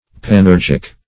Search Result for " panurgic" : The Collaborative International Dictionary of English v.0.48: Panurgic \Pan*ur"gic\, a. [Cf. Gr. panoyrgiko`s knavish.] Skilled in all kinds of work.